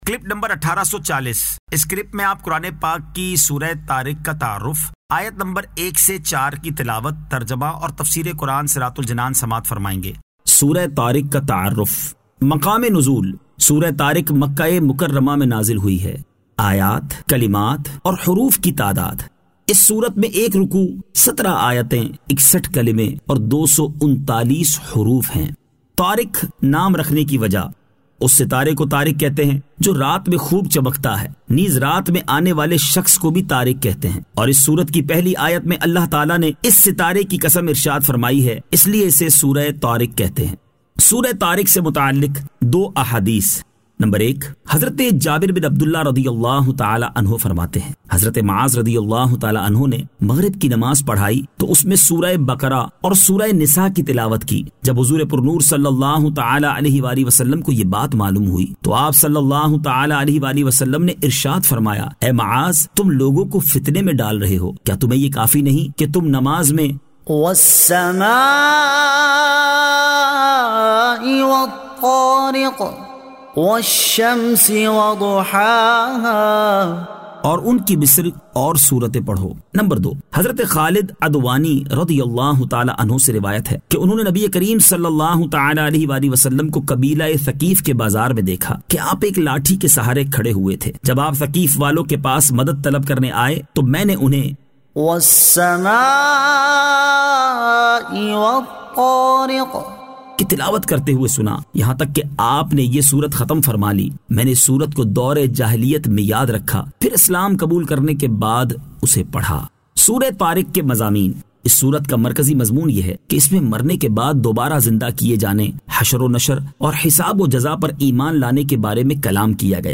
Surah At-Tariq 01 To 04 Tilawat , Tarjama , Tafseer